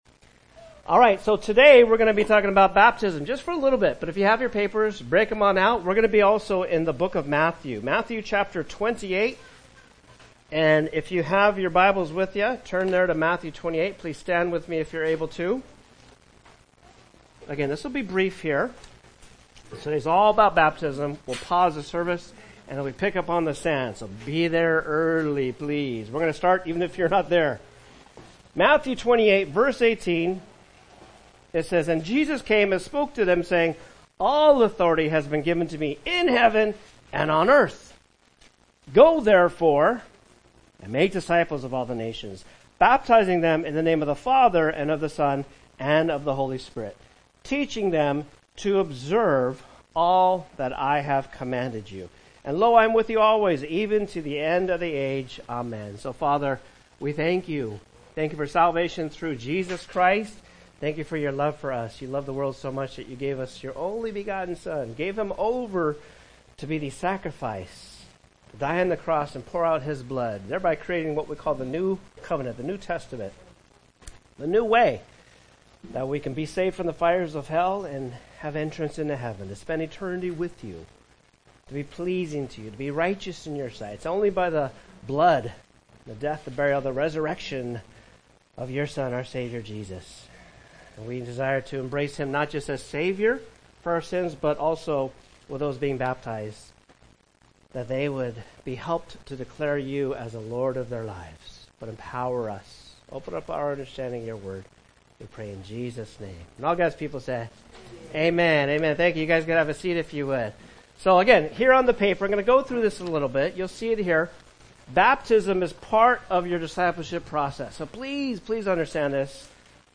Baptism Sunday 2025